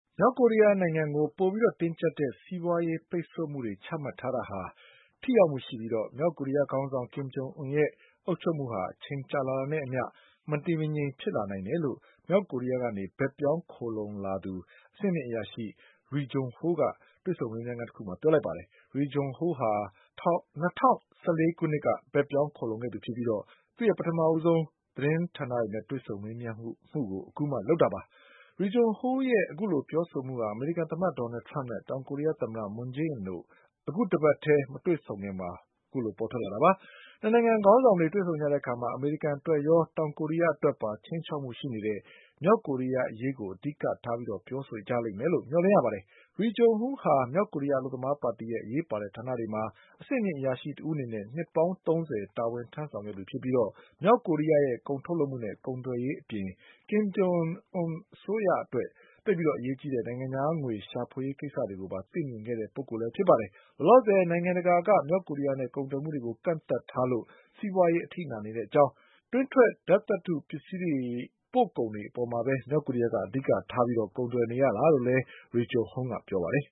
မြောက်ကိုရီးယား အဆင့်မြင့် အရာရှိ Ri Jong Ho နဲ့ ဗွီအိုအေ ကိုရီးယား ဌာန တွေ့ဆုံမေးမြန်းခန်း။